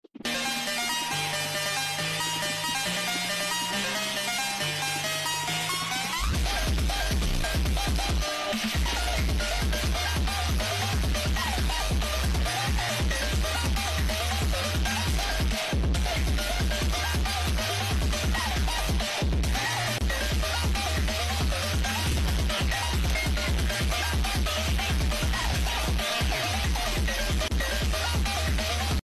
subway surfers watermelon coin